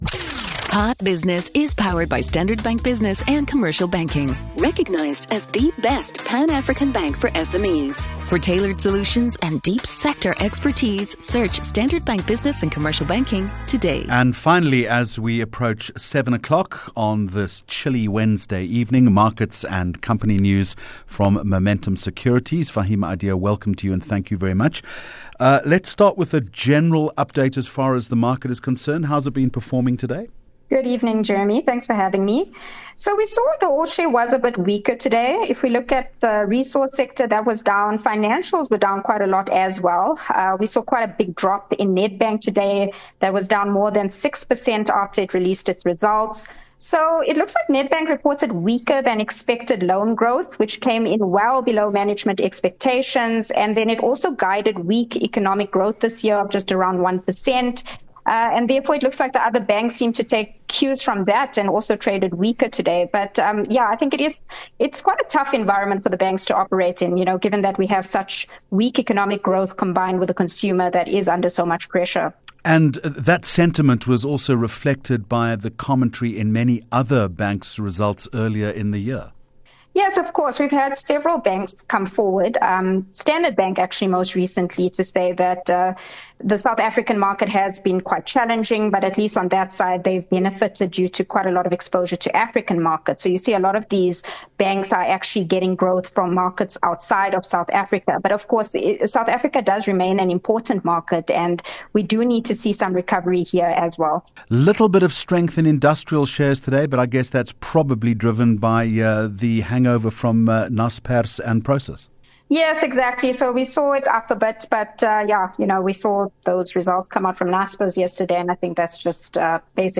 HOT Business with Jeremy Maggs, powered by Standard Bank 25 Jun Hot Business Interview